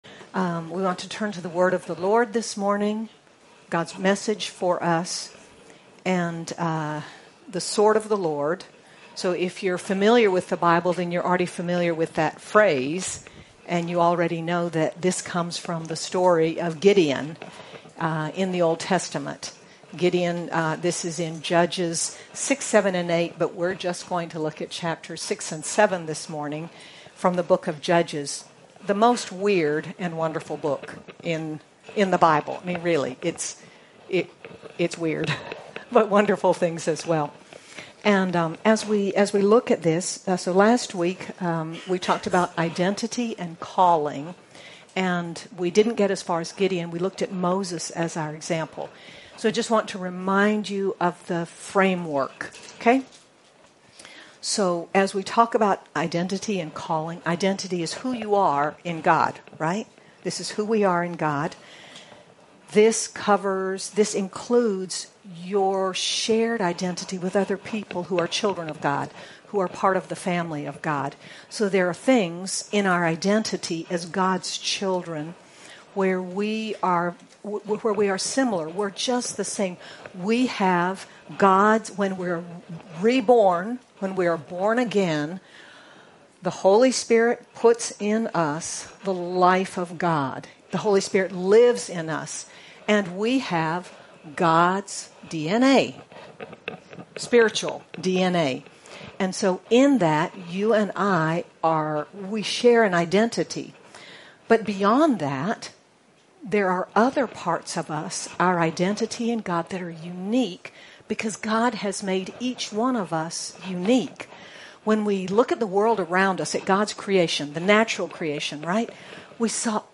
Feb 07, 2026 The Sword of the Lord (Identity and Calling) MP3 SUBSCRIBE on iTunes(Podcast) Notes Discussion In the conclusion to Identity and Calling, we are encouraged by the example of Gideon, a fearful but willing man who asks and receives ongoing reassurance that he IS a mighty warrior, that God is with him and that God has called him for His purposes. Sermon by